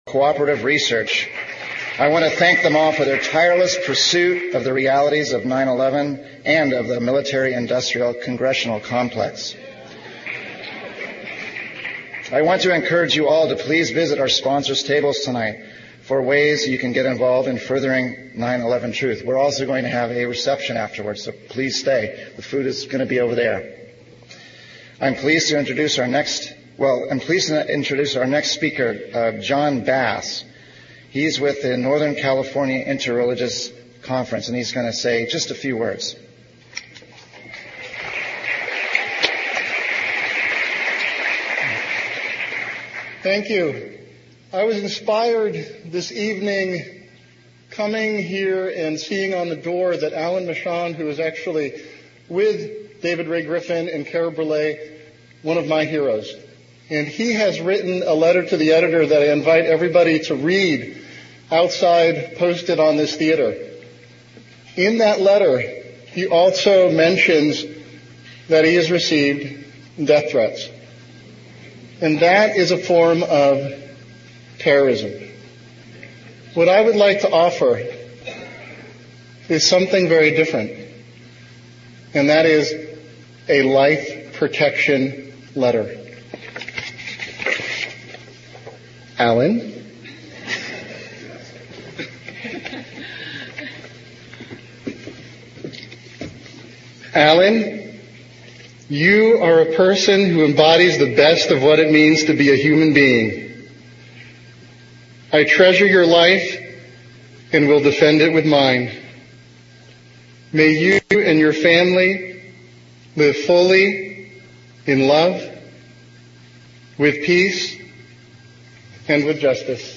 He covers the major points that refute the US government official story. There is also an interesting question and answer session. (The Grand Lake Theater is in Oakland California.)